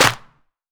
Claps
DrClap7.wav